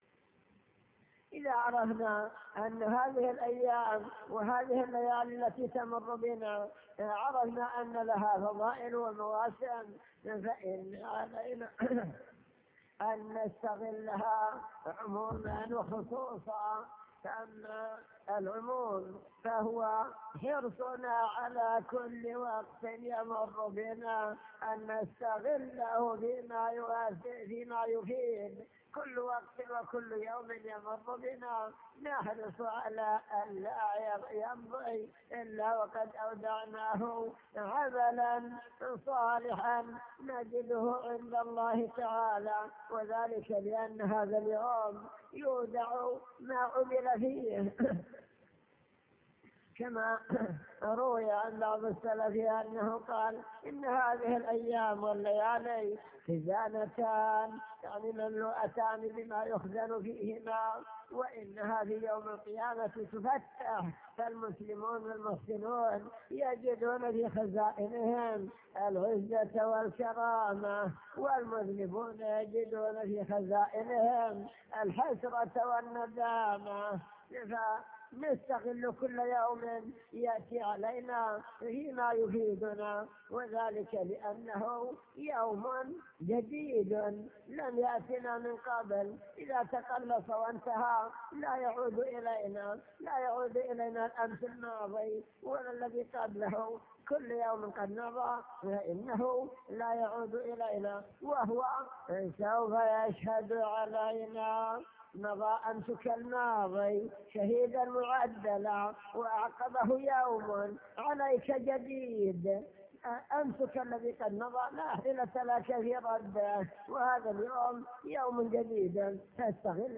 المكتبة الصوتية  تسجيلات - محاضرات ودروس  محاضرة بعنوان المسلم بين عام مضى وعام حل